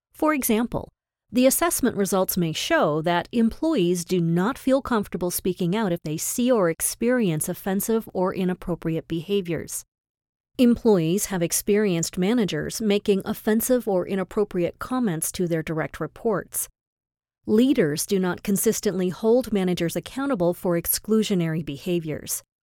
English (Canadian)
E-learning
Neumann TLM 102 Microphone